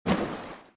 dive-splash.ogg